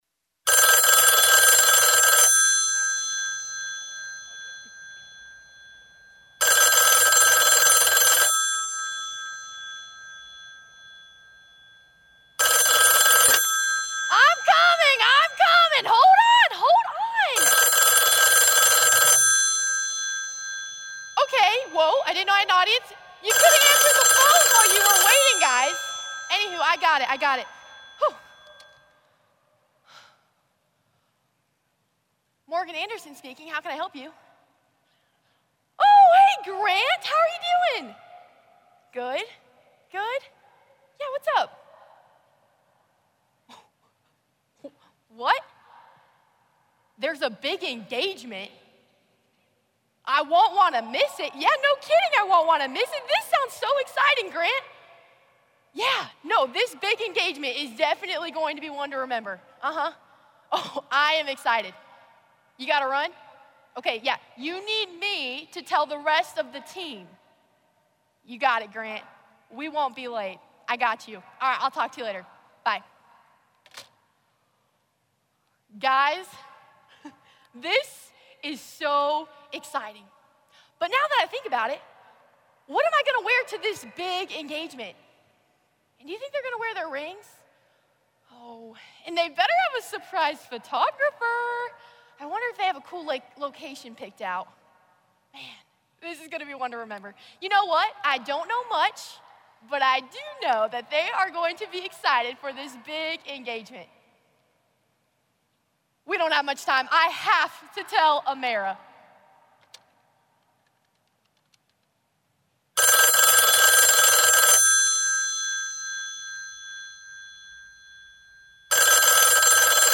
The 97th National FFA Convention and Expo is underway, with the 2023-24 National FFA officer team beginning the first session with the Convention Kickoff.